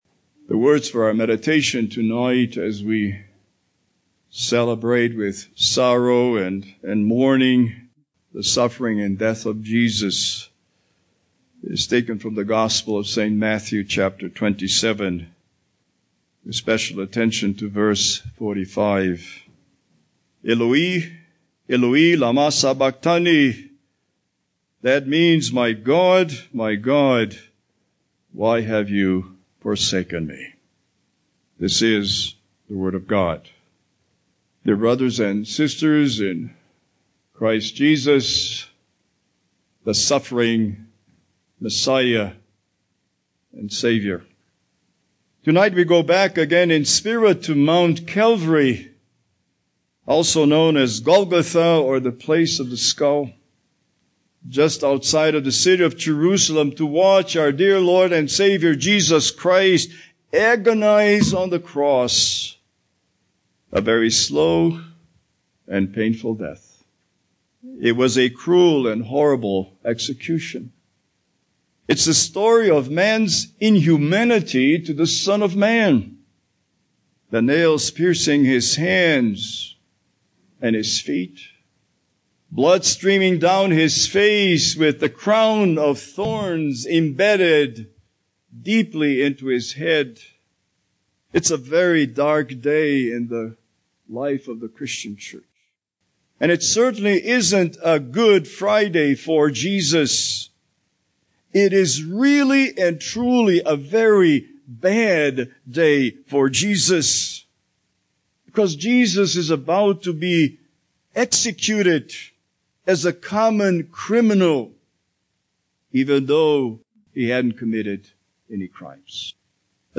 Holiday Sermons Passage: Matthew 27:1-66 Service Type: Good Friday « Surely not I